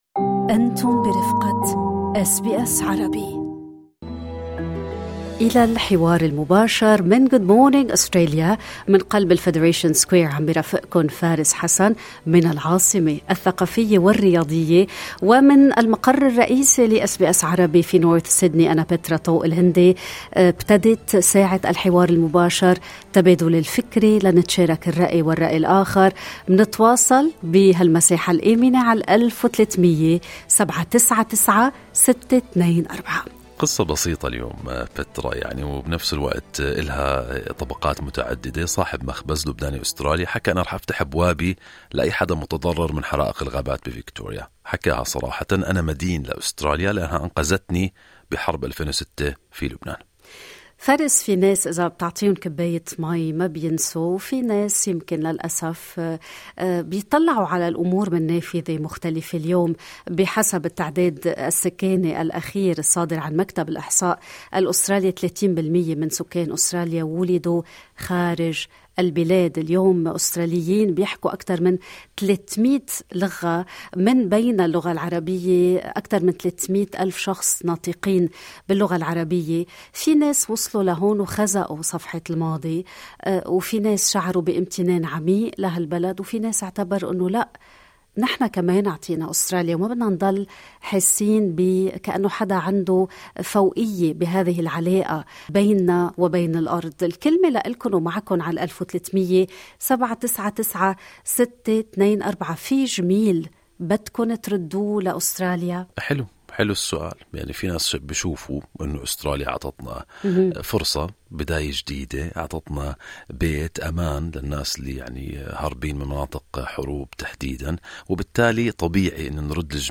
استمعوا الى عينة من آراء الجالية العربية حول هذا الطرح، في حوار غني وملهم في الملف الصوتي المرفق بالصورة أعلاه.
Between Gratitude and Partnership: How Do Migrants View Their Complex Relationship with Australia? Tune in to a rich talkback on “Good Morning Australia.”